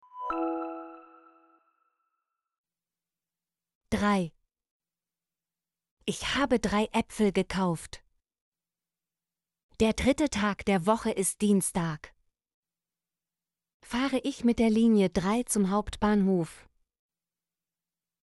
drei - Example Sentences & Pronunciation, German Frequency List